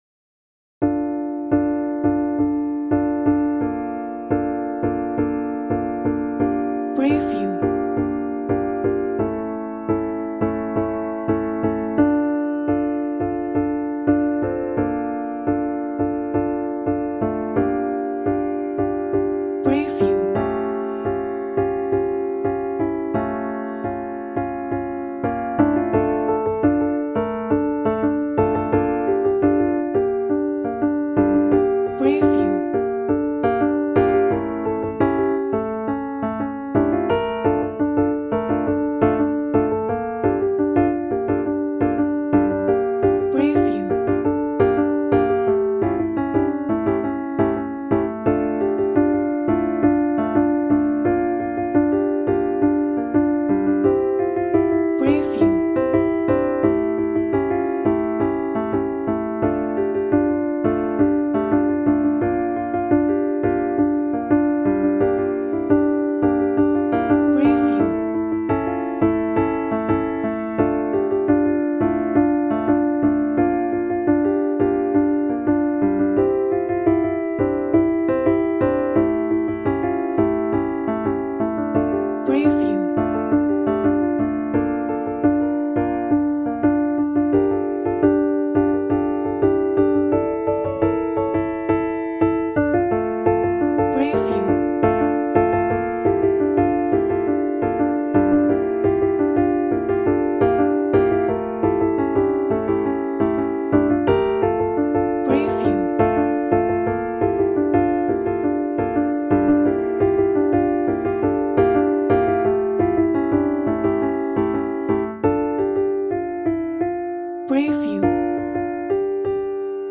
Тип: acapella Вид хору: SSAA Жанр: пісня К-сть сторінок